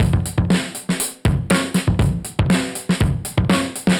Index of /musicradar/dusty-funk-samples/Beats/120bpm/Alt Sound